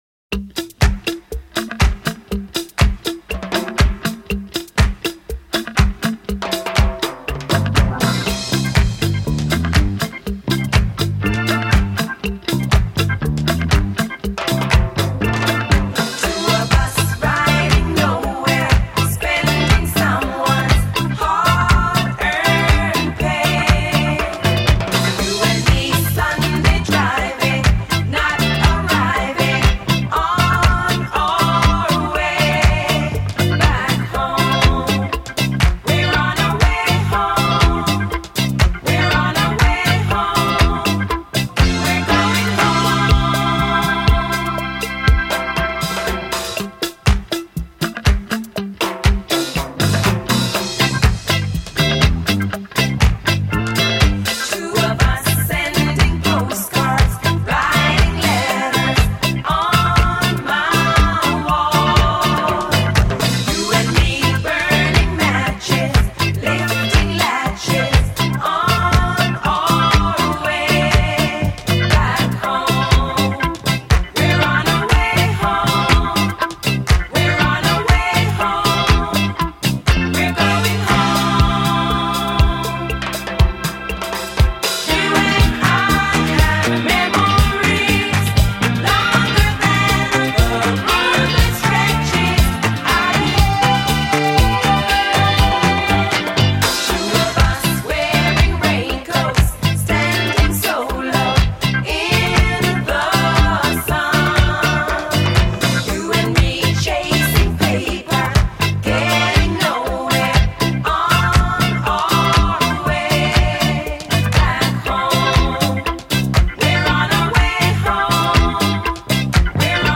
专辑风格：迪斯科